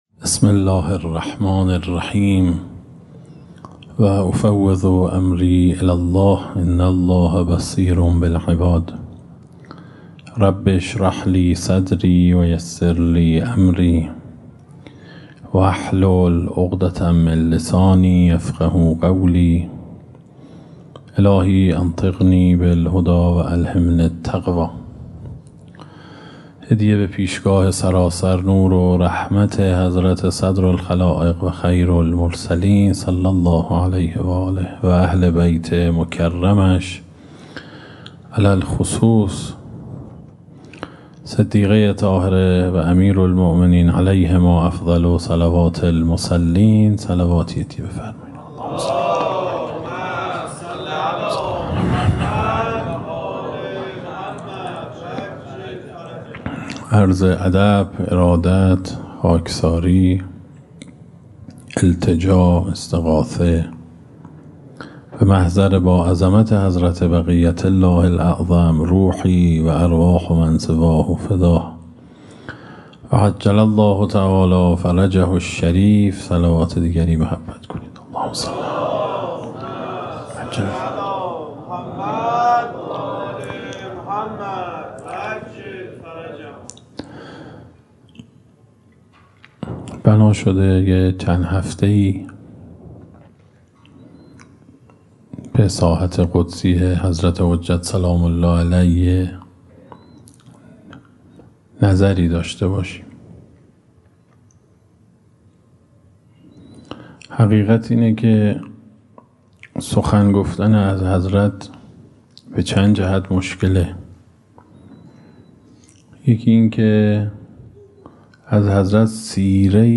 در مسجد گیاهی تجریش
سخنرانی